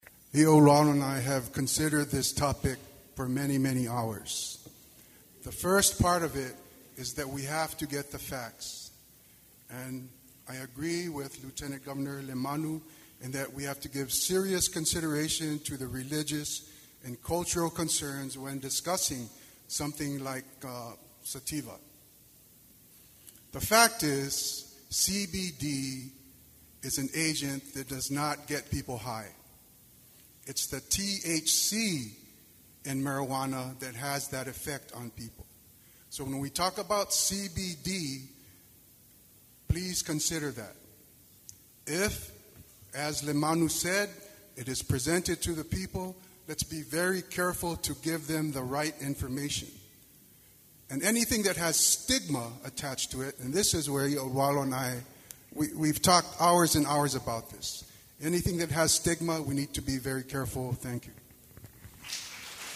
We conclude our coverage of the Chamber of Commerce, “Lets Talk Business Forum,” with gubernatorial teams with this final segment.